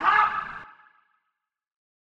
Vox (Ha-$hoot).wav